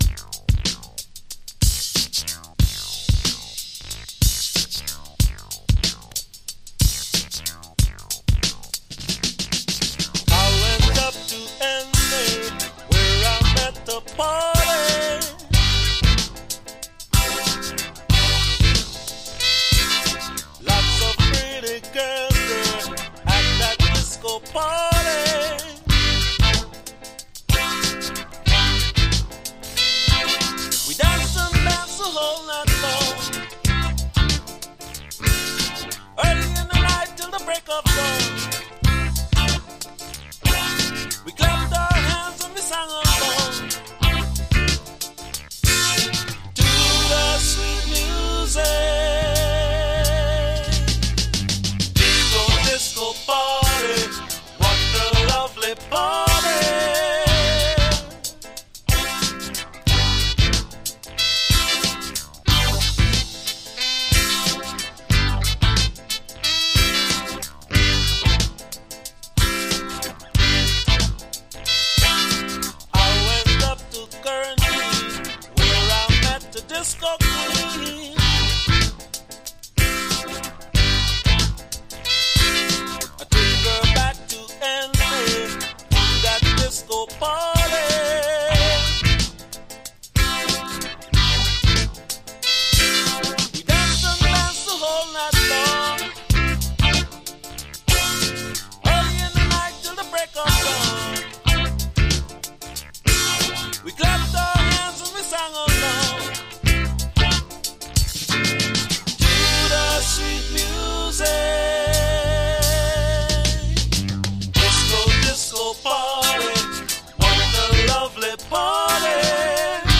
Backing Vocals
Bass
Drums
Guitar
Congo Drums
Percussion
Piano
Saxophone
Strings
Synthesizer
Trumpet
Super sweet groover, hope you’ll like it – Enjoy !